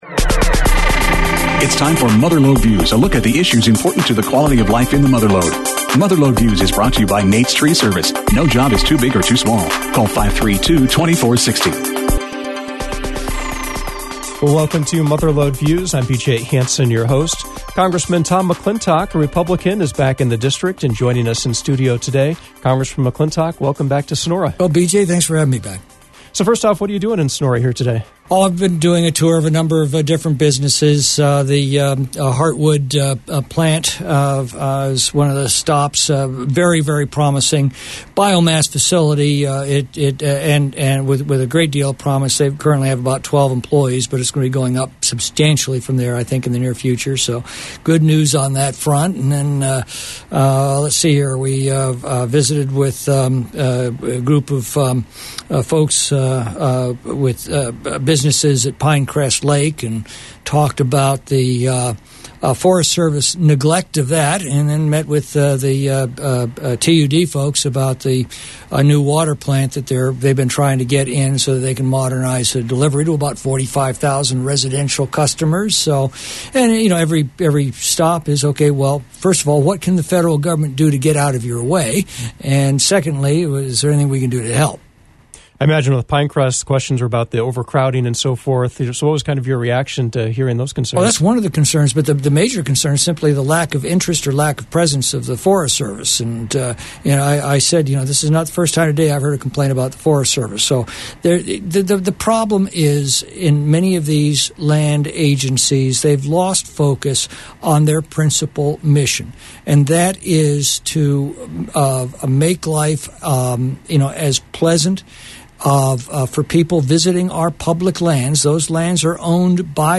The Republican Congressman who represents the Mother Lode, Tom McClintock, spoke about issues facing both the region and the nation.
Congressman Tom McClintock MLV Full Interview